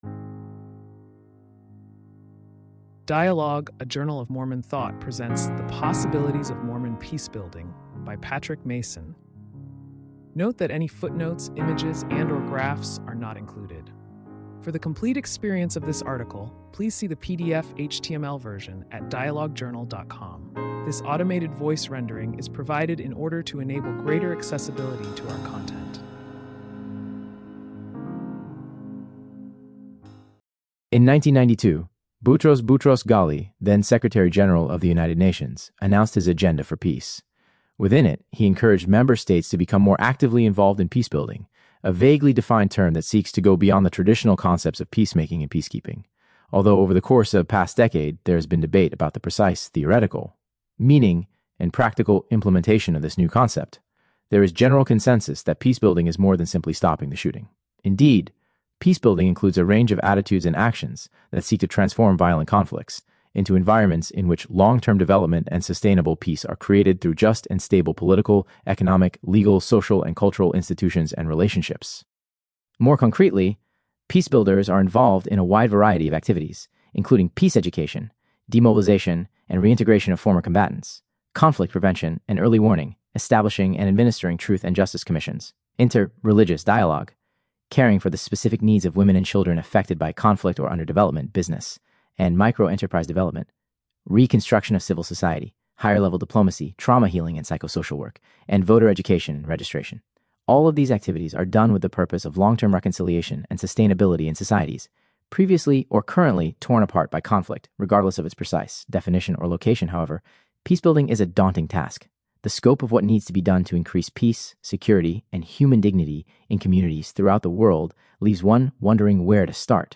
This automated voice rendering is provided in order to enable greater accessibility…